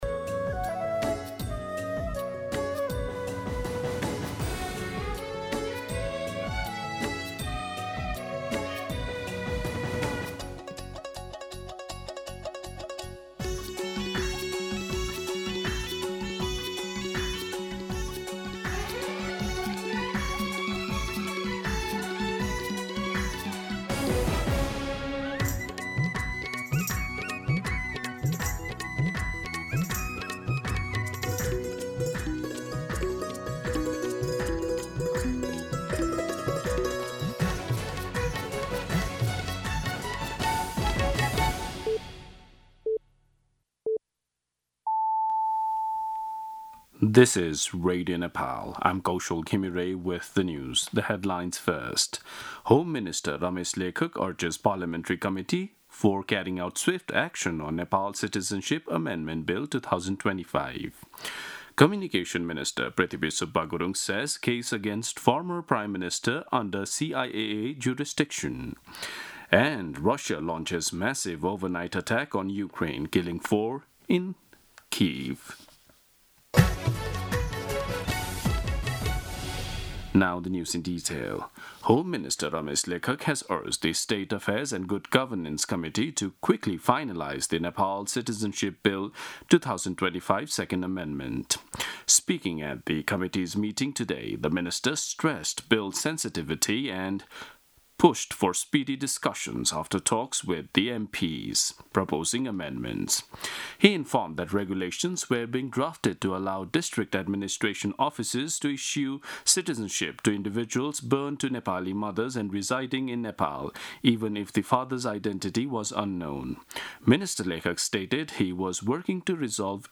दिउँसो २ बजेको अङ्ग्रेजी समाचार : २३ जेठ , २०८२
Day-News-English-2-pm-.mp3